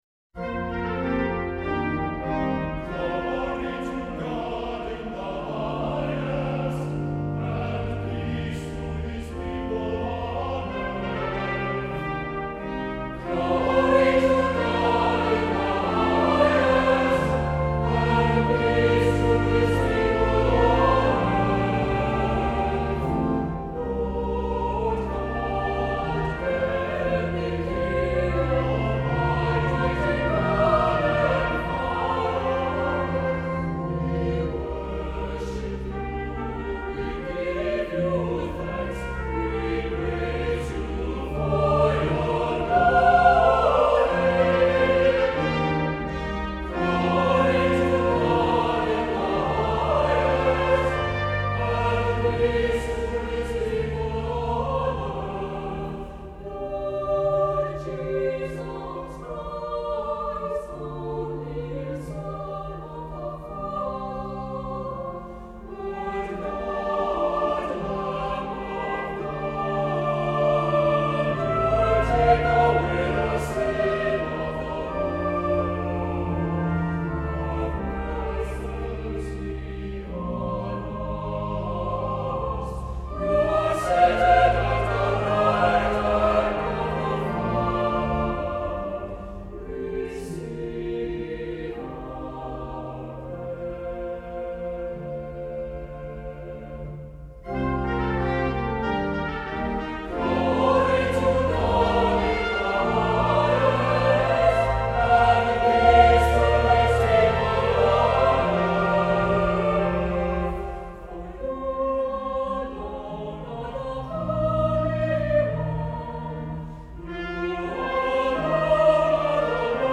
SATB/congregation, organ, optional trumpet (c. 3:00)